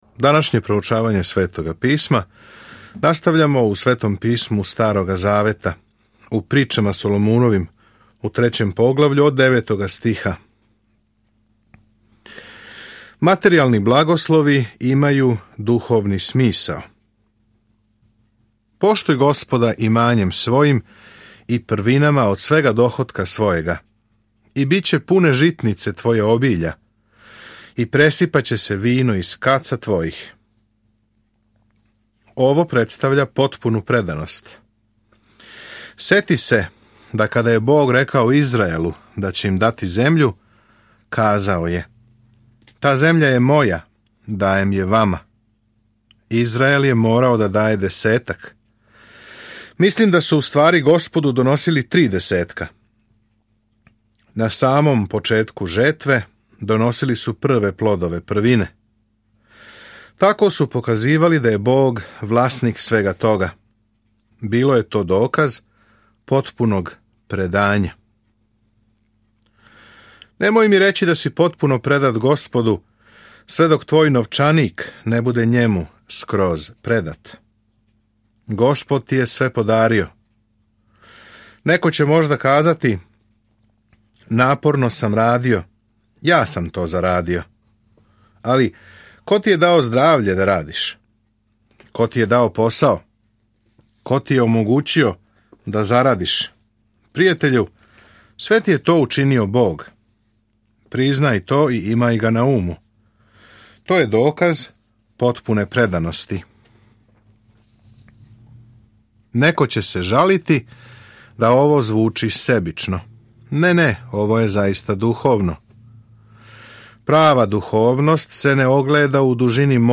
Sveto Pismo Priče Solomonove 3:9-35 Dan 5 Započni ovaj plan Dan 7 O ovom planu Пословице су кратке реченице извучене из дугог искуства које поучавају истину на начин који се лако памти – истине које нам помажу да доносимо мудре одлуке. Свакодневно путујте кроз Пословице док слушате аудио студију и читате одабране стихове из Божје речи.